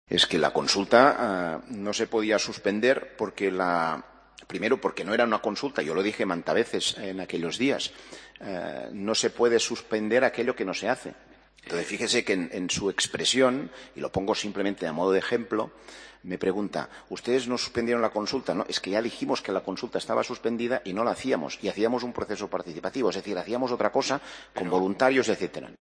El diputado de PDeCAT y exconseller de Presidencia Francesc Homs declara ante el Supremo, en el juicio contra él por el 9N